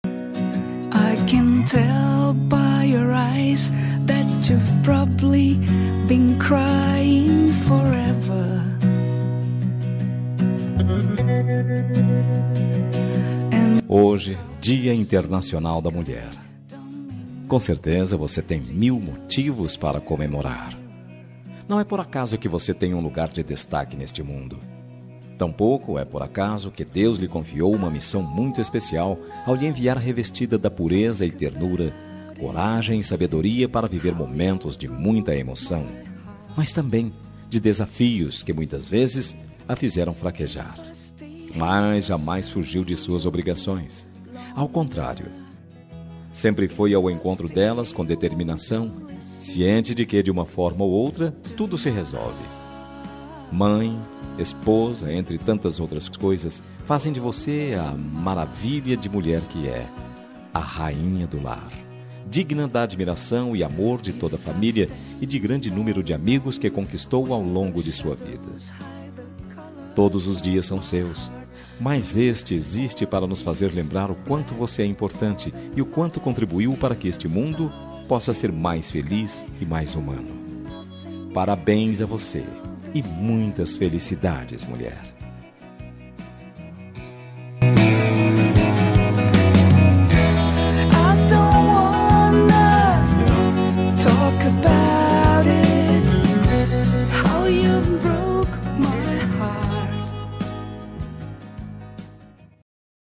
Dia das Mulheres Neutra – Voz Masculina – Cód: 5272